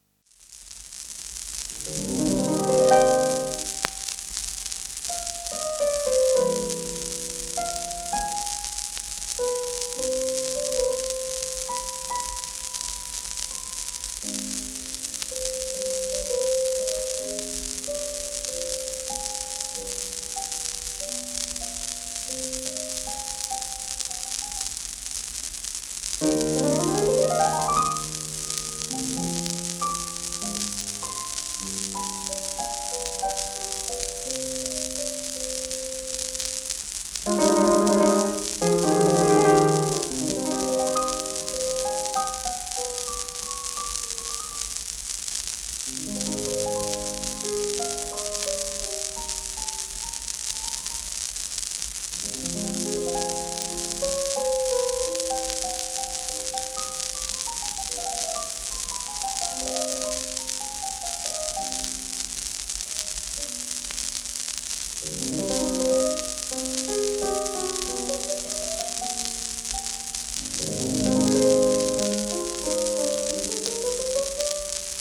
エドウィン・フィッシャー(P:1886-1960)
幻想曲 ハ短調 k396（モーツァルト）
シェルマン アートワークスのSPレコード